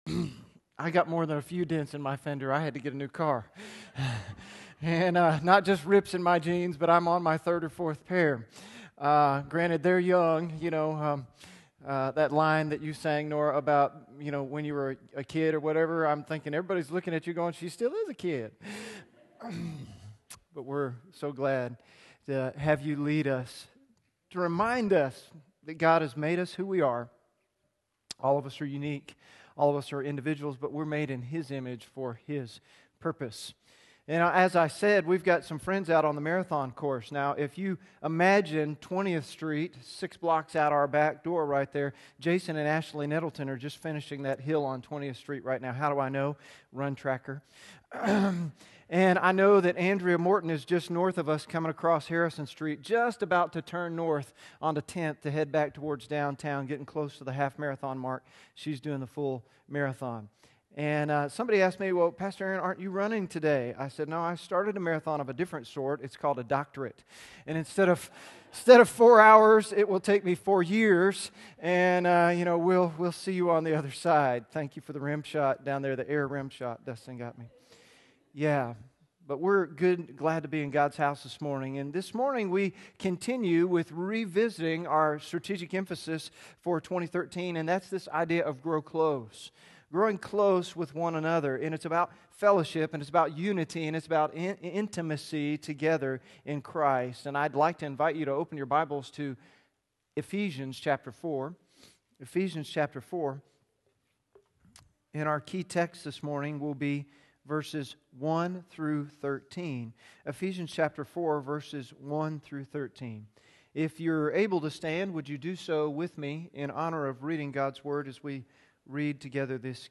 Guest Sermon